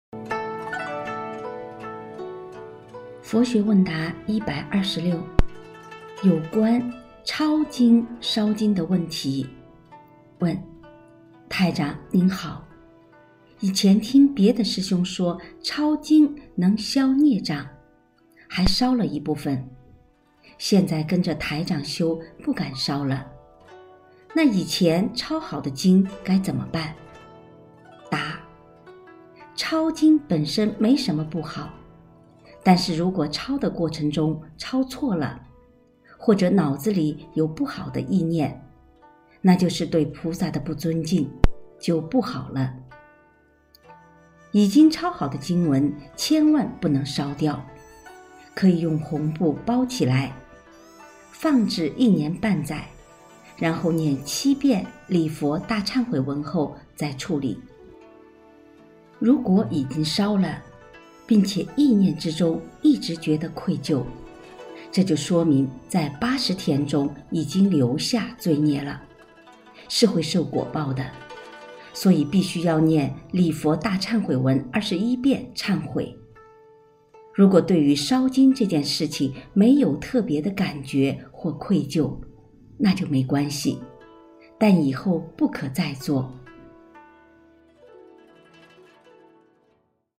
126．有关抄经烧经的问题  佛学问答（诵读版）